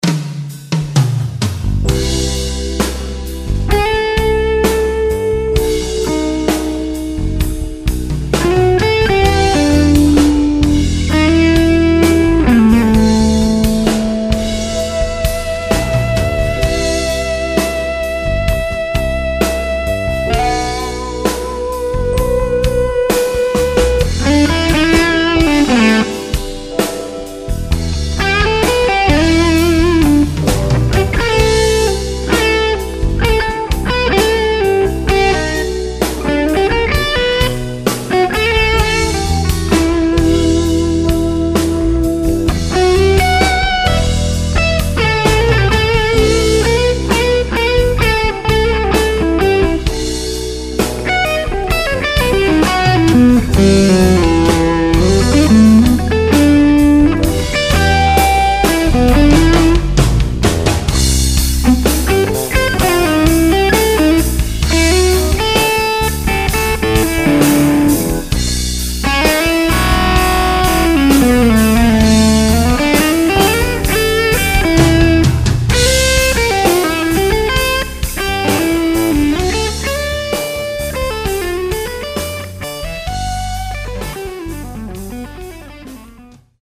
Full blown Brownnote Bluesmaster.
HRM was set with treble about 47%, mids only on about 20%, and bass only on about 25%.
SM57 and G1265.
What I am specifically referring to is after your souble stops, the low note has sort of a buzz around it which is what I could not dial out of my BM.